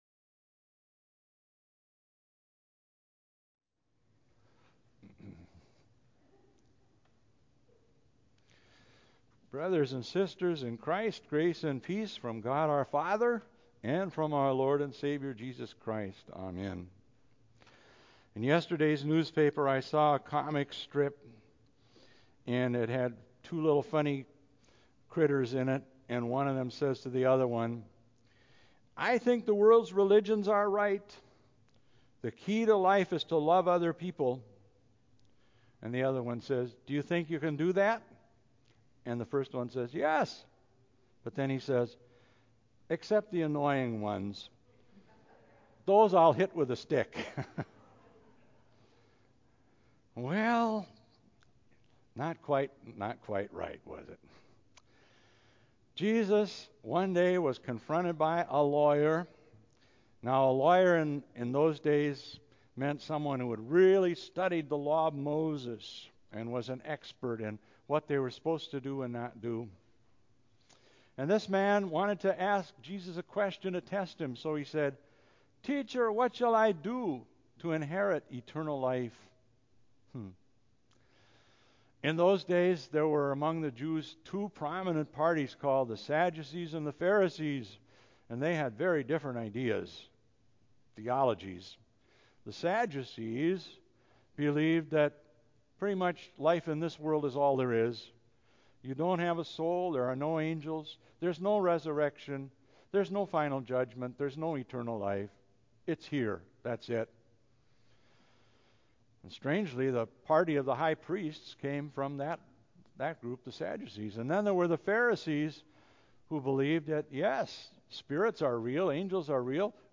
church-sermon714-CD.mp3